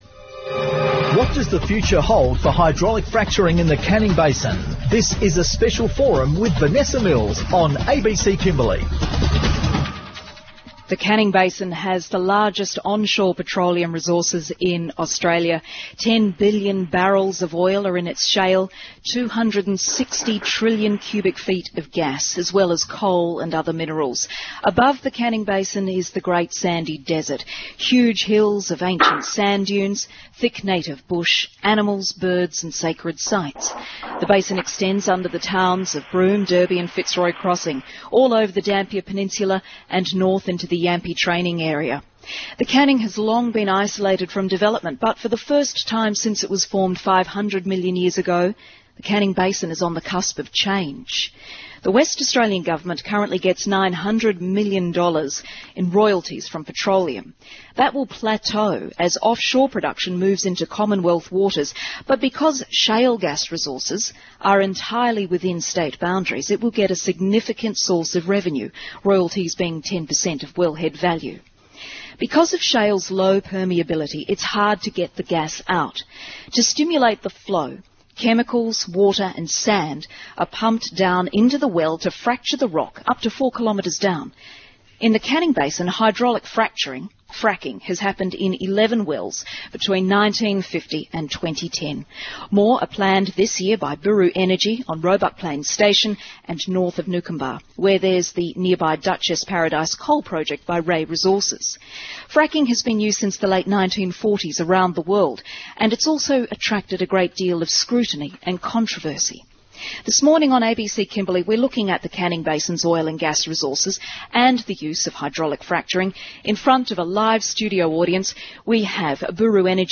About 40 people gathered at the ABC studios in Broome to hear a discussion on the practice of hydraulic fracturing, with a focus on the Canning Basin.